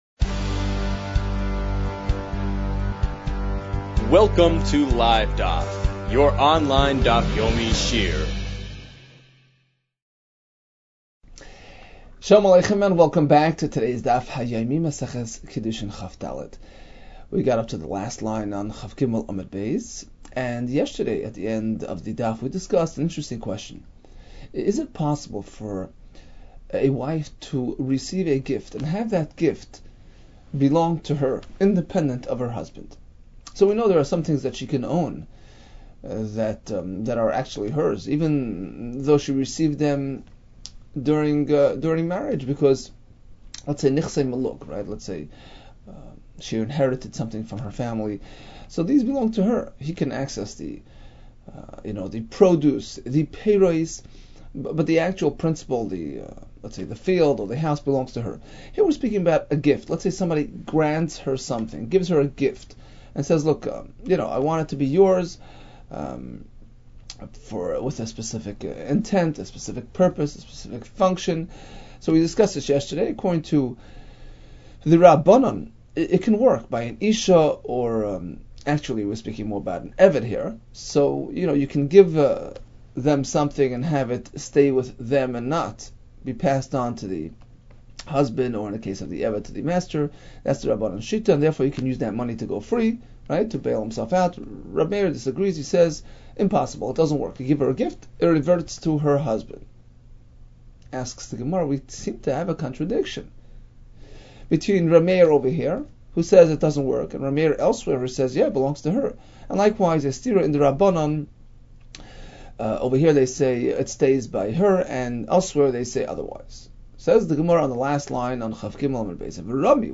Kiddushin 23 - קידושין כג | Daf Yomi Online Shiur | Livedaf